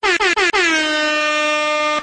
AirAirAirAir Horn
air-horn-x3.mp3